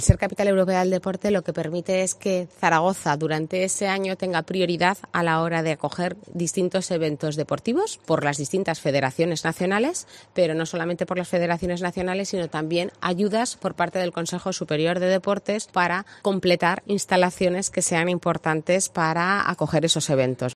El Pabellón Príncipe Felipe ha acogido este viernes el acto central de presentación de la candidatura zaragozana.
La alcaldesa explica lo que supondrá para Zaragoza ser Capital Europea del Deporte en 2026.